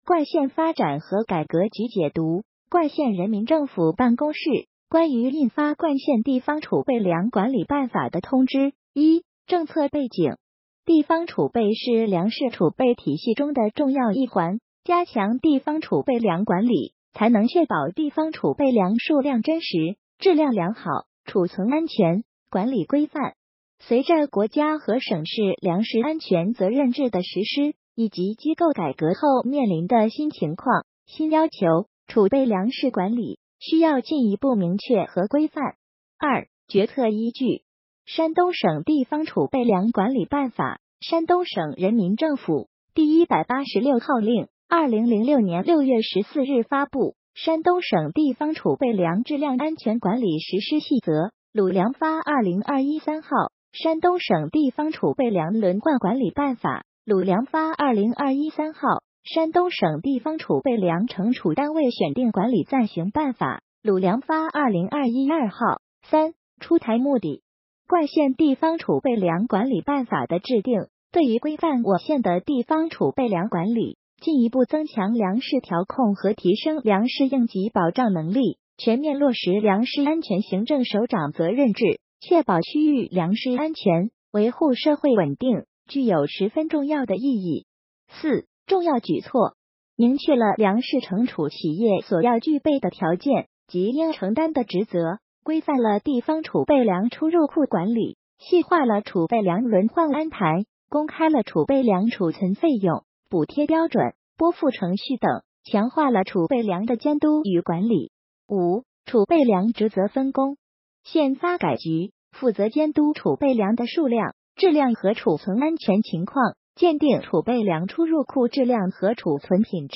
音频解读：《冠县人民政府办公室关于印发<冠县地方储备粮管理办法>的通知》.mp3